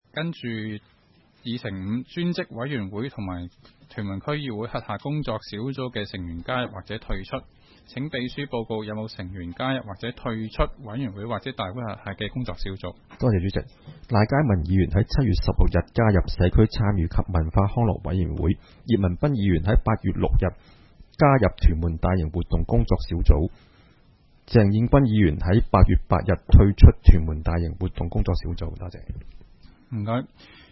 区议会大会的录音记录
屯门区议会会议室